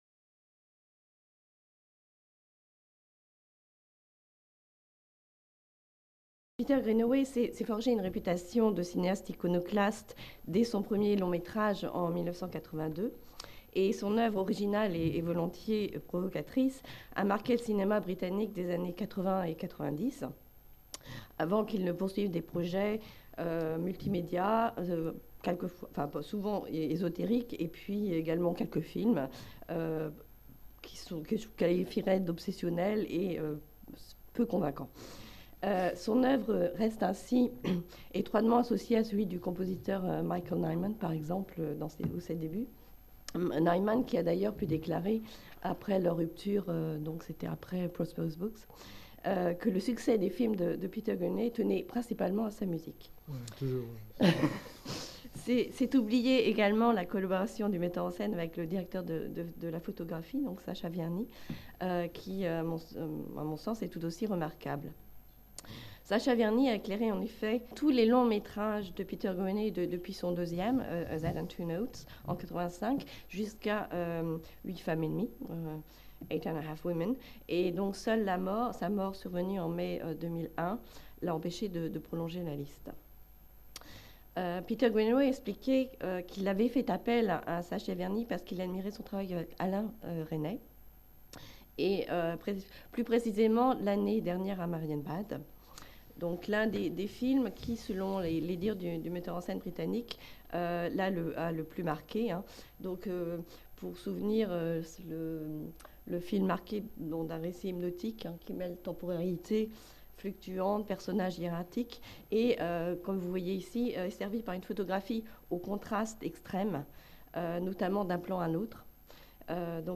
Cette communication a été filmée dans le cadre d'une journée d'étude du LASLAR consacrée au cinéma britannique.